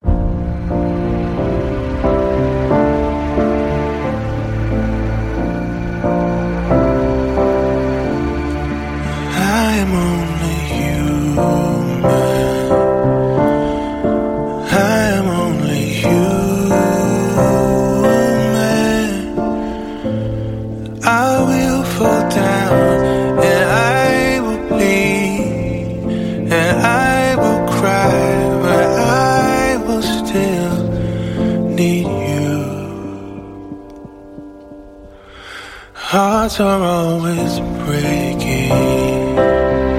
пианино , поп